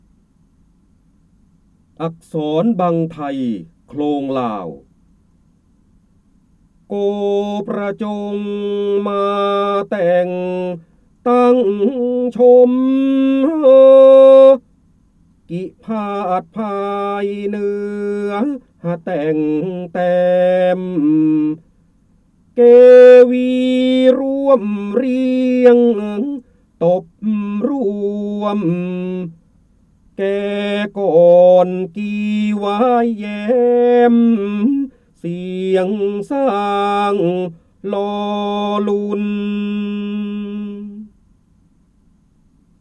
เสียงบรรยายจากหนังสือ จินดามณี (พระโหราธิบดี) อักษรบังไทโคลงลาว
คำสำคัญ : การอ่านออกเสียง, ร้อยกรอง, ร้อยแก้ว, พระโหราธิบดี, จินดามณี, พระเจ้าบรมโกศ